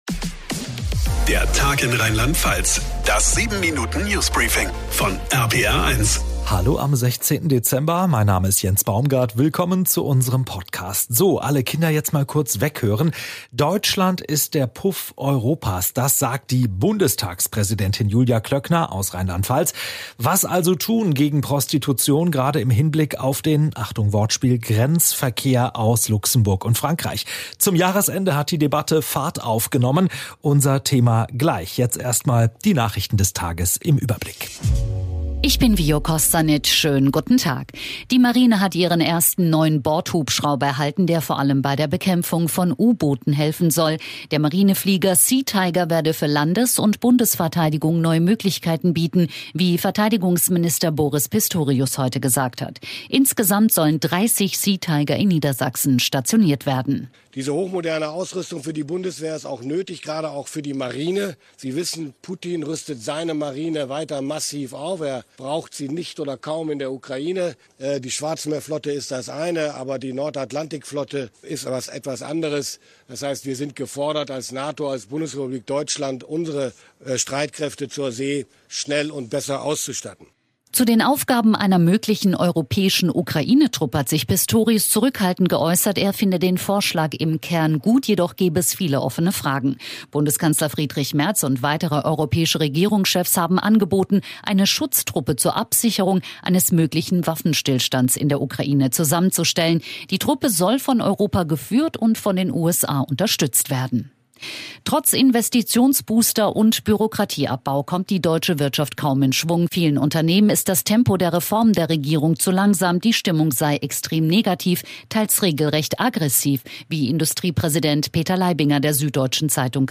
Das 7-Minuten News Briefing von RPR1.